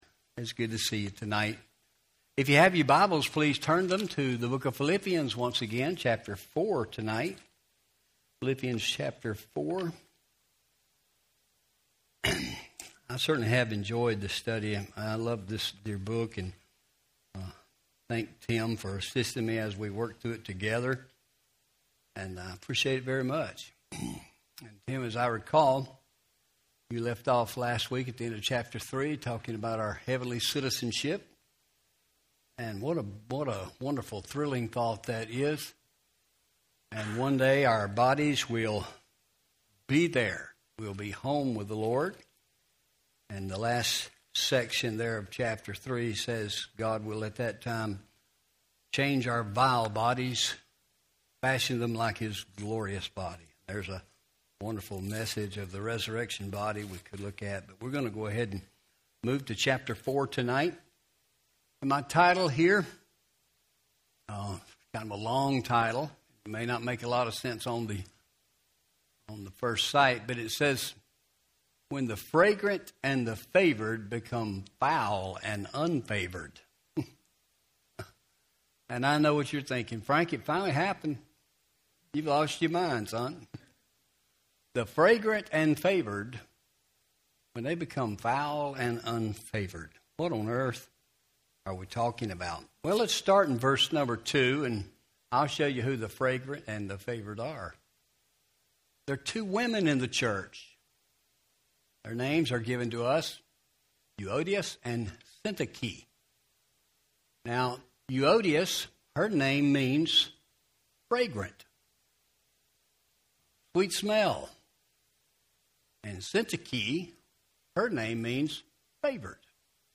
Philippians 4:2-7 Audio Sermon